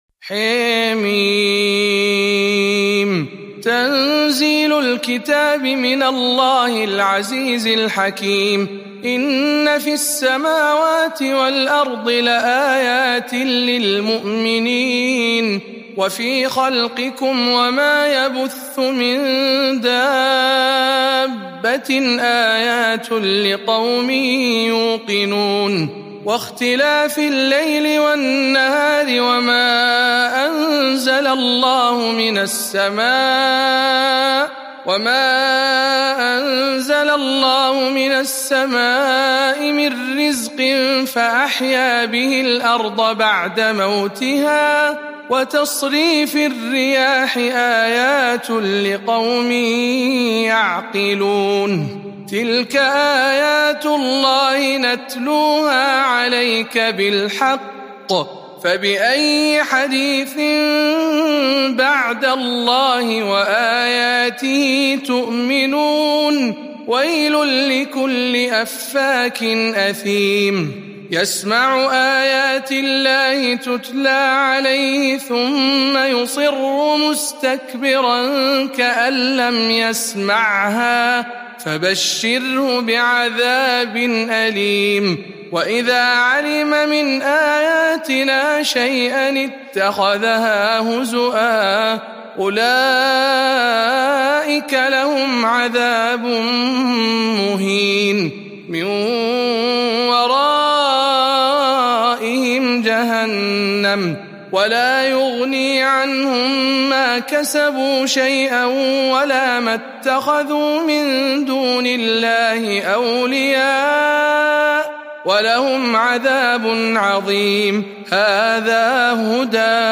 سورة الجاثية برواية شعبة عن عاصم